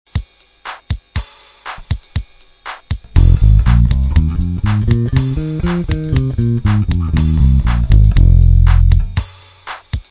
Les gammes à la basse: quel travail indispensable!!!
- Pentatonique mineure
pentatonique_mineure.wav